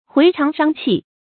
回腸傷氣 注音： ㄏㄨㄟˊ ㄔㄤˊ ㄕㄤ ㄑㄧˋ 讀音讀法： 意思解釋： 見「回腸蕩氣」。